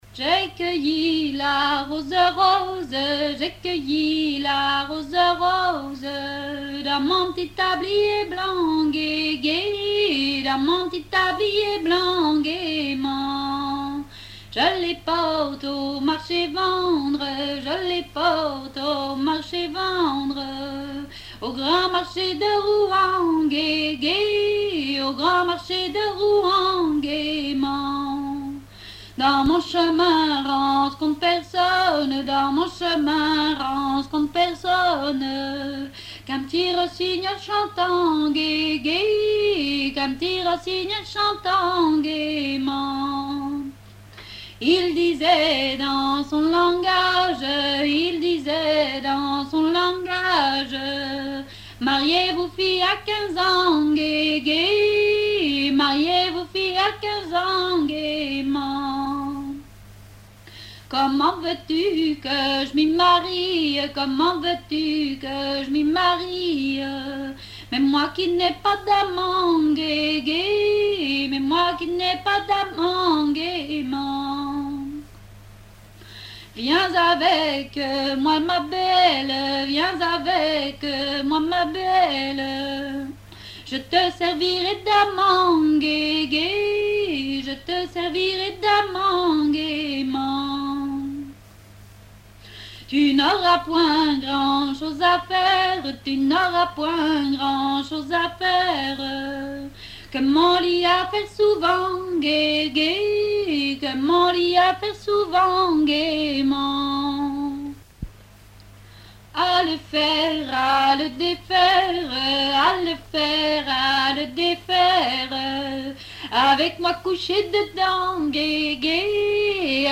Roche-sur-Yon (La)
répertoire de chansons traditionnelles
Pièce musicale inédite